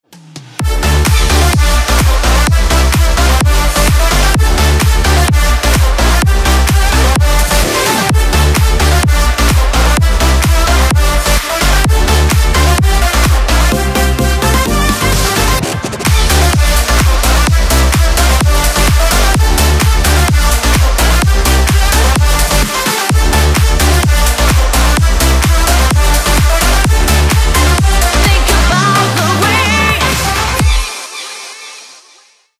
• Качество: 320, Stereo
громкие
dance
электронная музыка
club